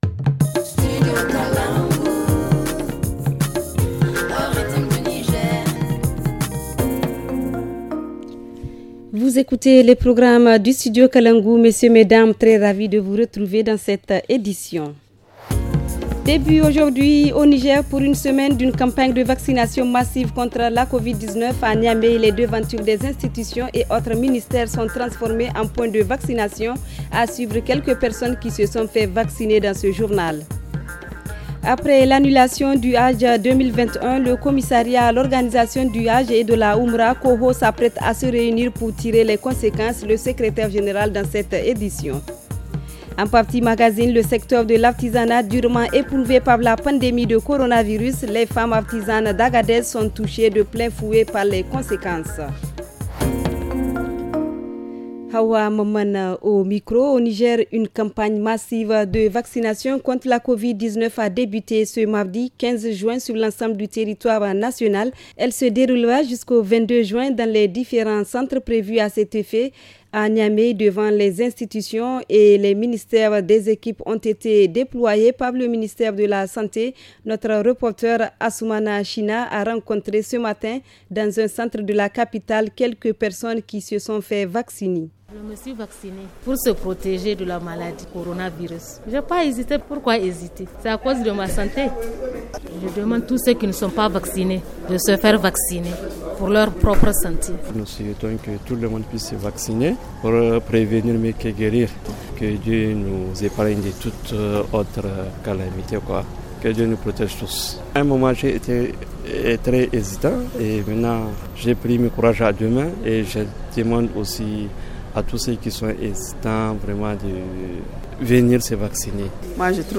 Le journal du 15 juin 2021 - Studio Kalangou - Au rythme du Niger